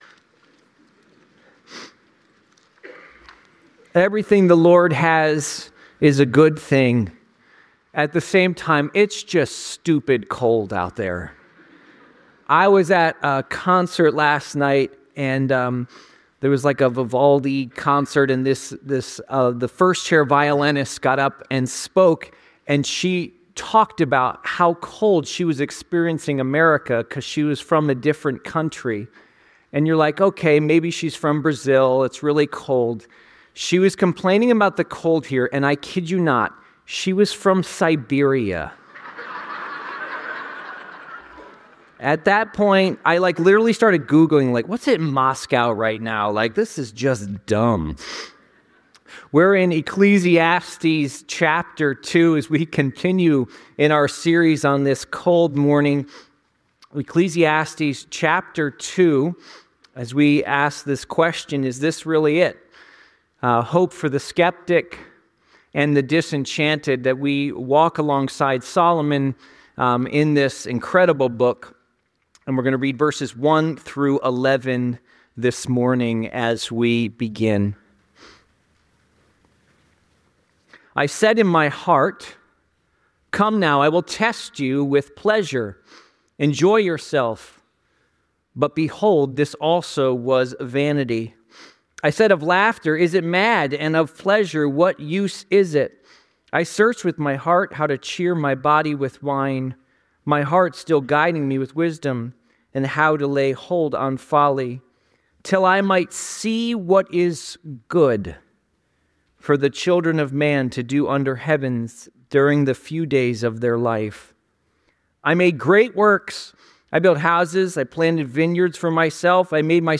This sermon focuses on Solomon's exploration of the question 'What is the good life?'